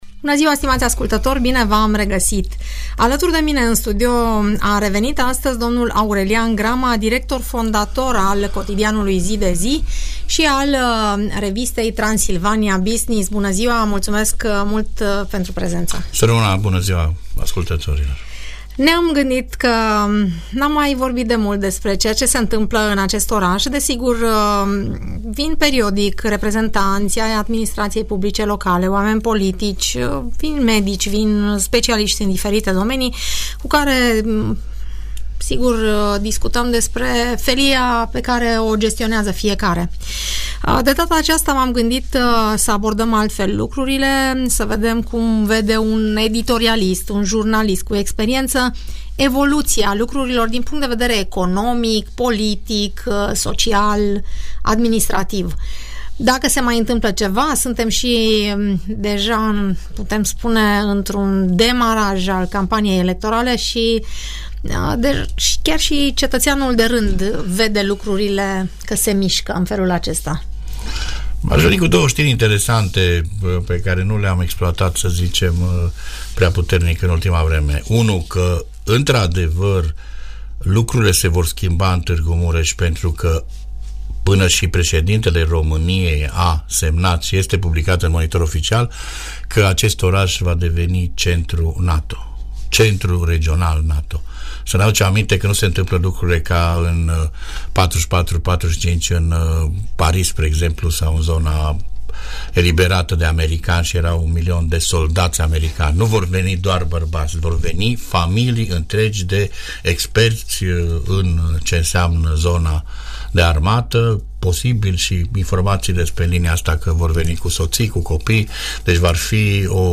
Urmărește discuția moderată